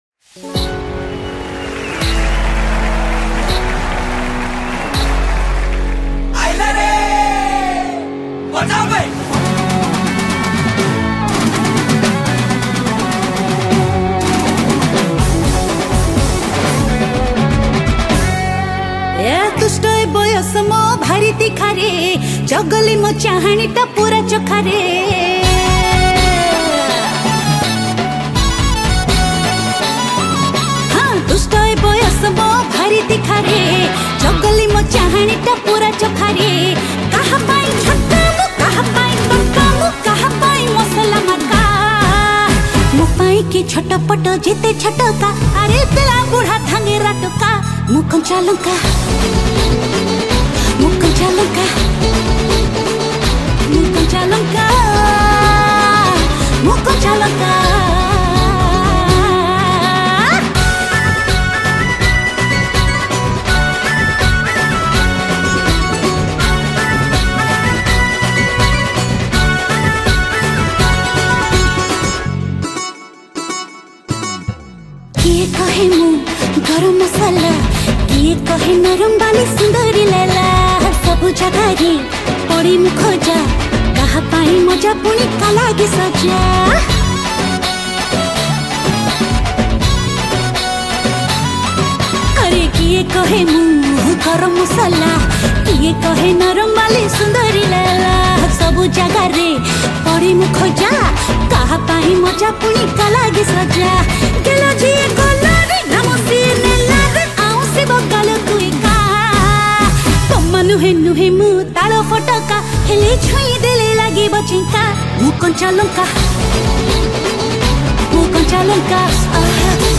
Back vocal
Keys
Drum
Guitar